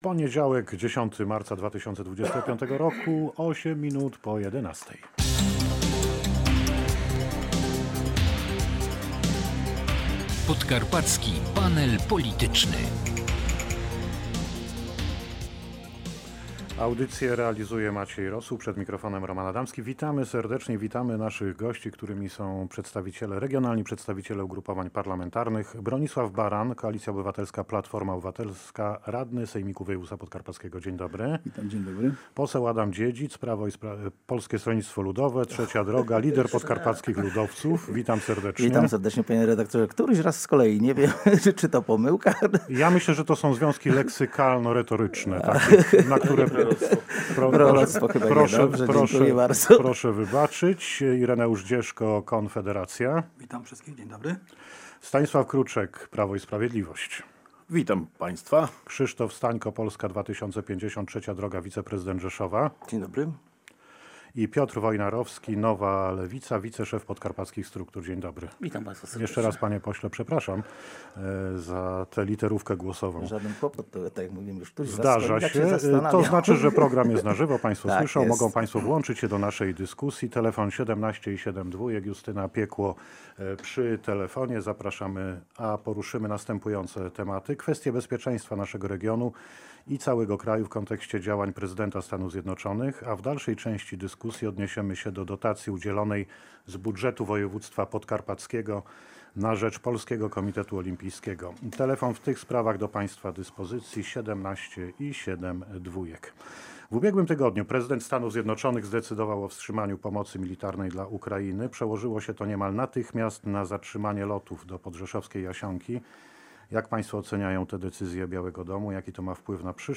Stanisław Kruczek radny sejmiku z Prawa i Sprawiedliwości powiedział, że była słuszna decyzja, dzięki której zyskają sportowcy z naszego regionu.
panel-polityczny.mp3